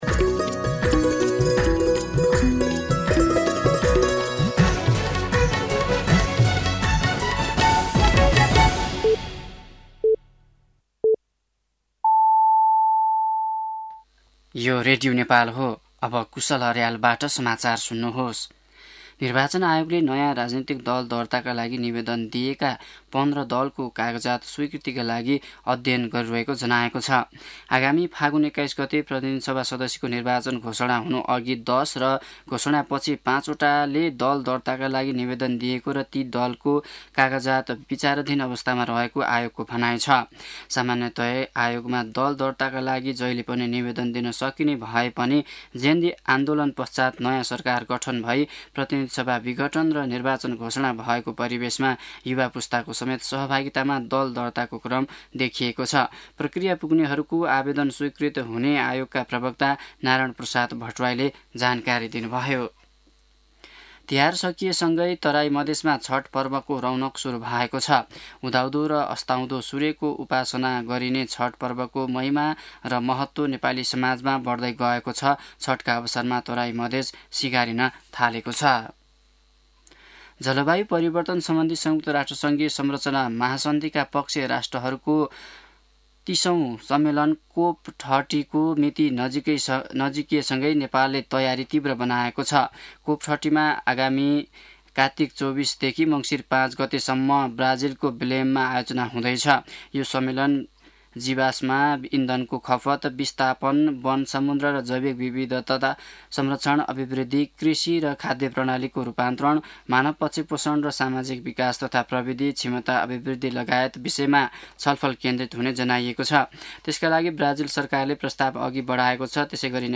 दिउँसो ४ बजेको नेपाली समाचार : ७ कार्तिक , २०८२
4-pm-Nepali-News-11.mp3